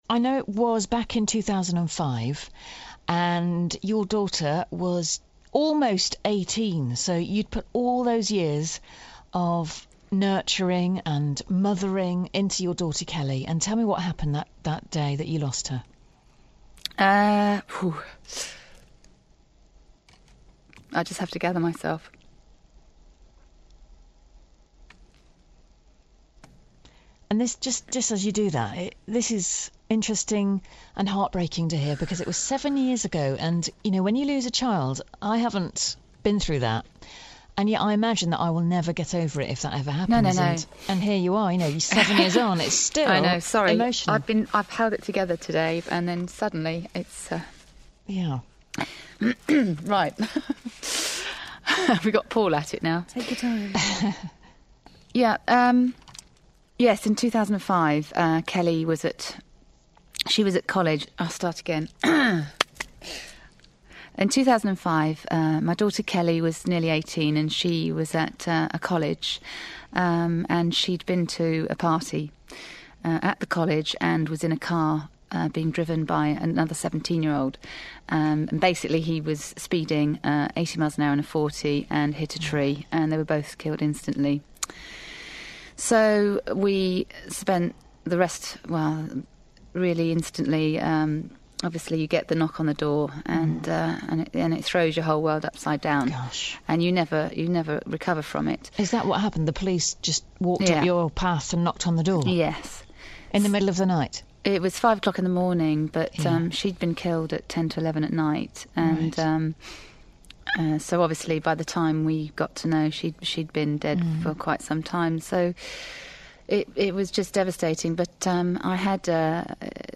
An live interview with the BBC regarding eSures DriveOFF app.